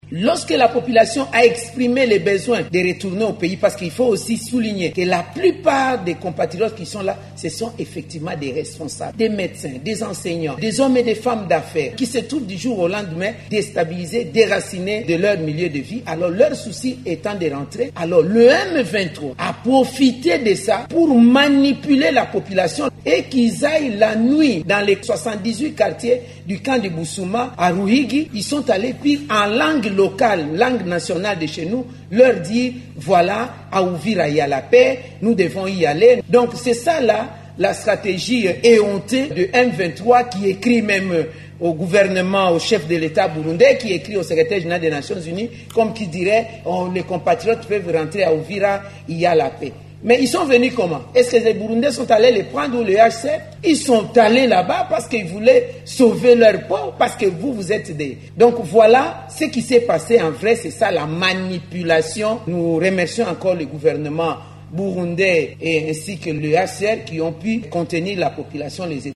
La ministre d’État des Affaires sociales s’est exprimée ainsi lors du briefing coanimé samedi avec son collègue de la Communication et Médias.